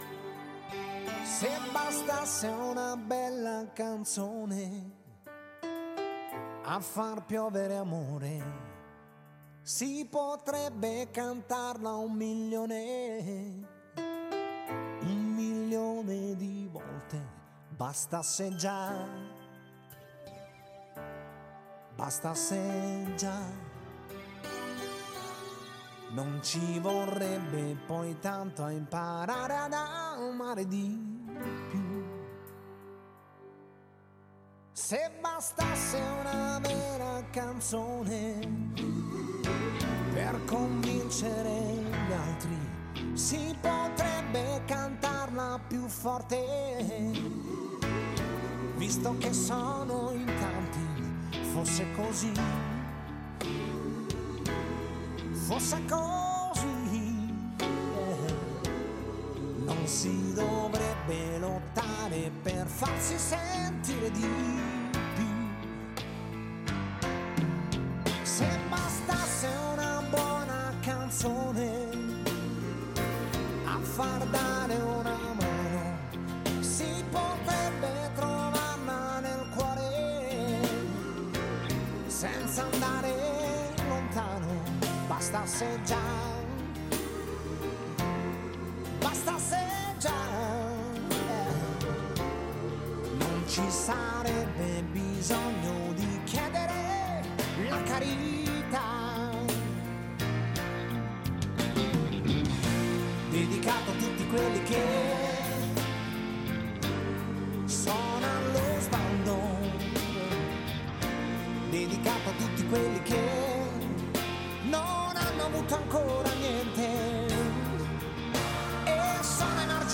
Ce midi, Souvenirs FM recevait les Lous Gouyats de l’Adou, groupe folklorique dacquois